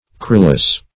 Corylus - definition of Corylus - synonyms, pronunciation, spelling from Free Dictionary